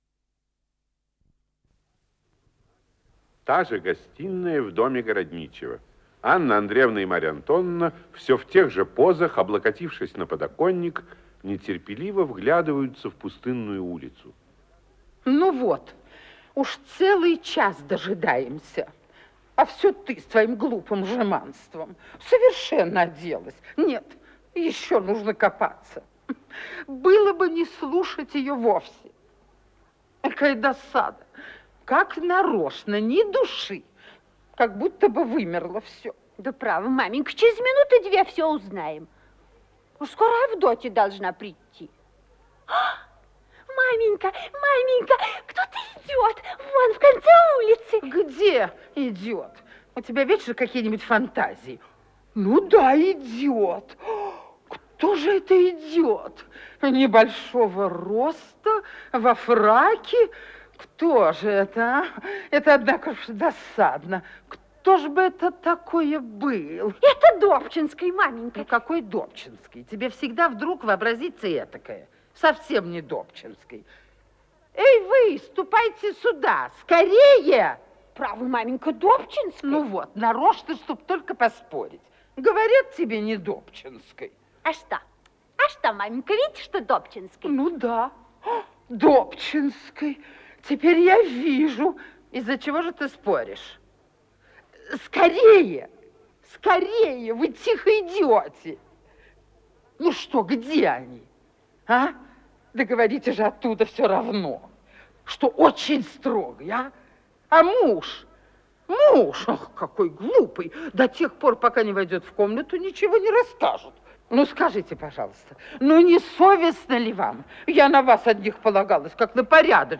Исполнитель: Артисты Государственного академического Малого театра СССР
Спектакль, 3-е действие